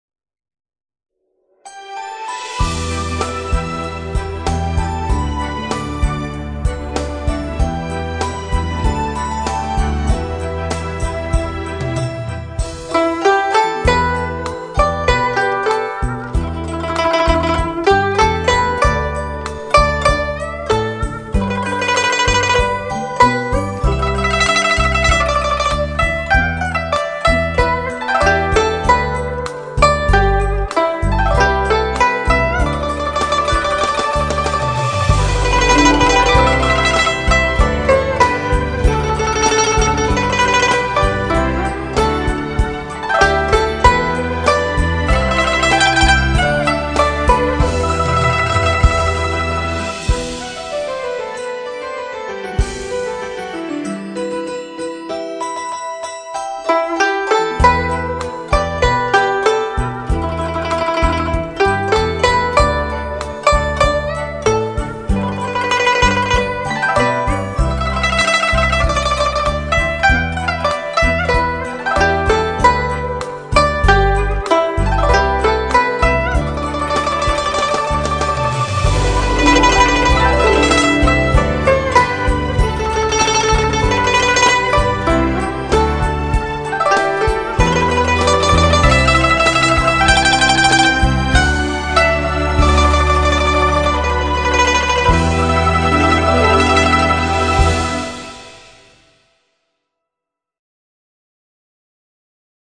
大陆经典歌曲民乐珍藏版
古筝